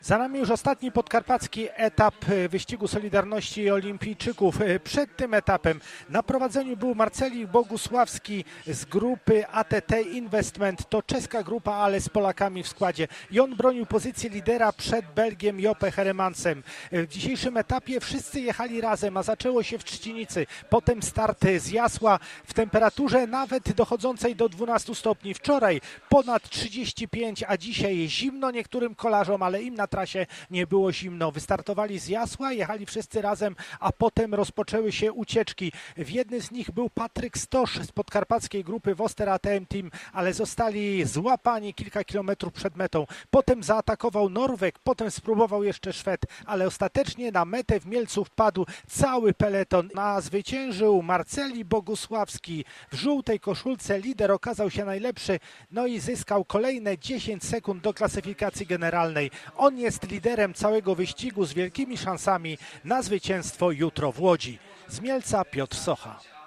korespa-wyscig.mp3